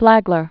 (flăglər), Henry Morrison 1830-1913.